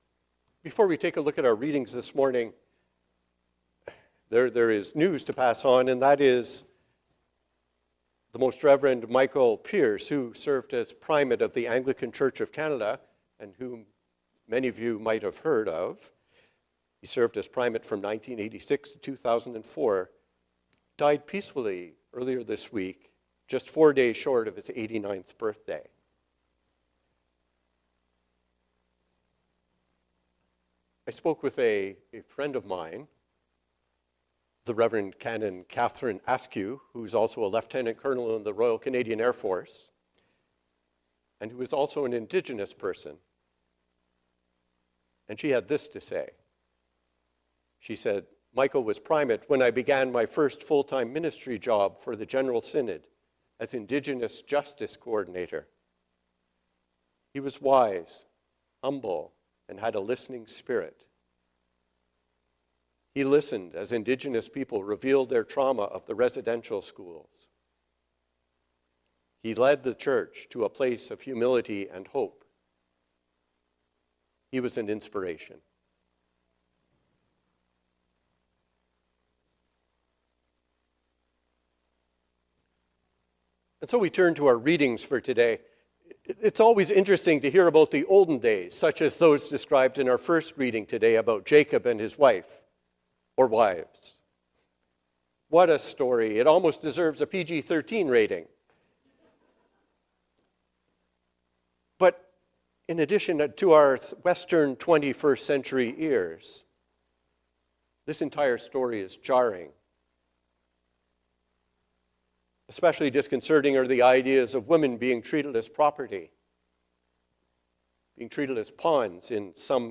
A Sermon for the Ninth Sunday after Pentecost